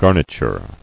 [gar·ni·ture]